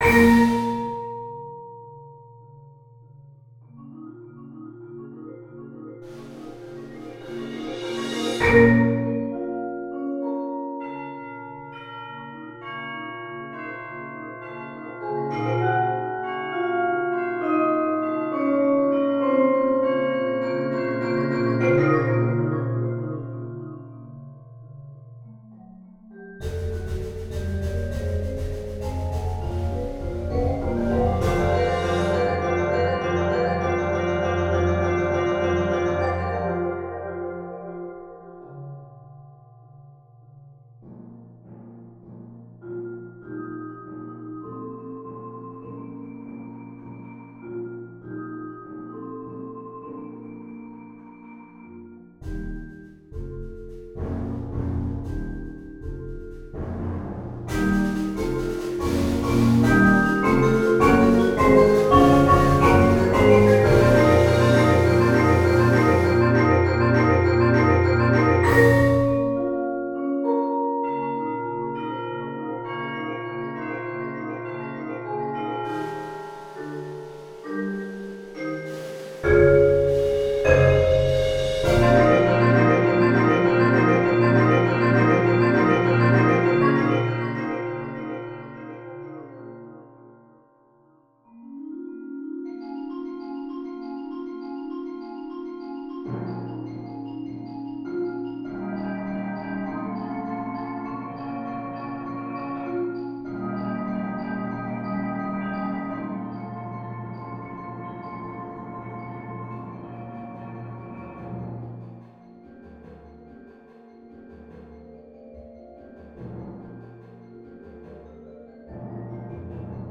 Genre: Percussion Ensemble
# of Players: 11
Vibraphone
Xylophone
Marimba 1 [4-octave]
Timpani
Chimes
Percussion 1 (Crash Cymbals, Suspended Cymbal)
Percussion 2 (Snare Drum, Conga or Djembe)
Percussion 3 (Bass Drum, Low Tom-Tom)